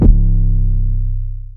REDD 808 (6).wav